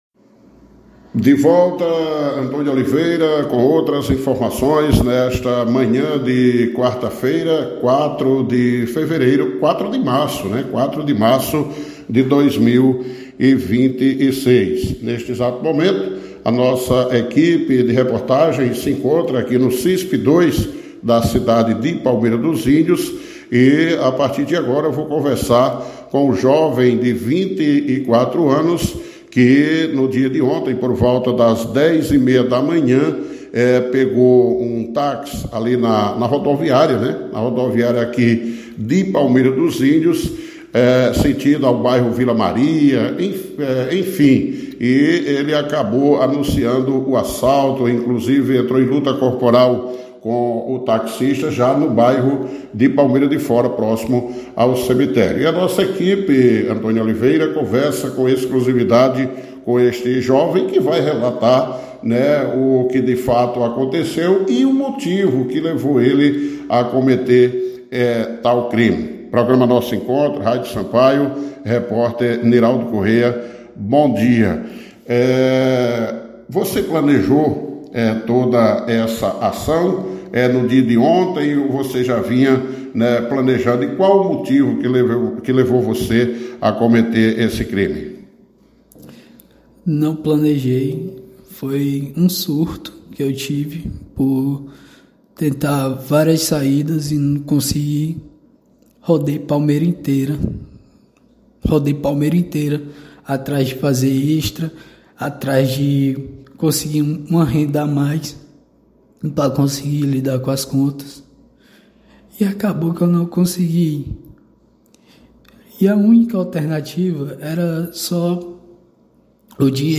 A entrevista foi realizada no Centro Integrado de Segurança Pública (CISP), onde o investigado permanece preso e aguarda audiência de custódia. Durante a conversa, o suspeito afirmou que não teria planejado o crime e atribuiu a ação a um “surto” motivado por dificuldades financeiras. Ele relatou que precisava de cerca de R$ 700 para quitar dívidas de aluguel, energia e cartão de crédito.